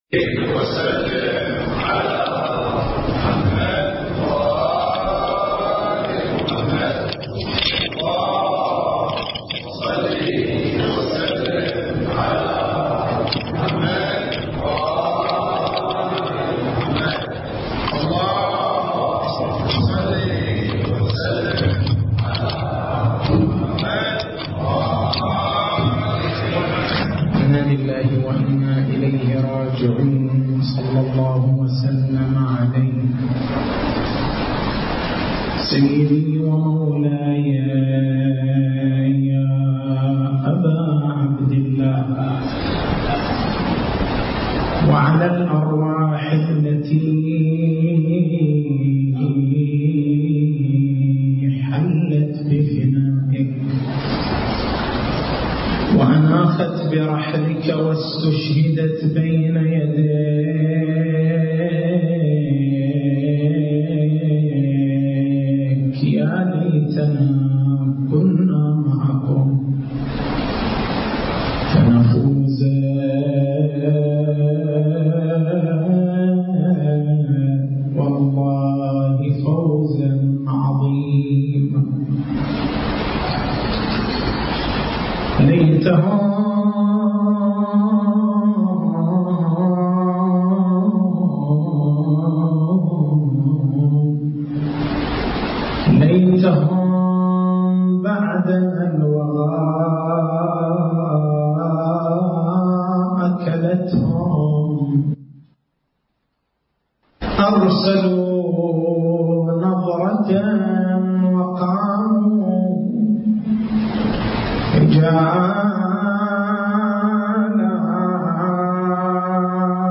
تاريخ المحاضرة: 08/09/1431 محور البحث: ما هي الفائدة من وجود الإمام المهدي (ع) وهو غائب عن الأنظار؟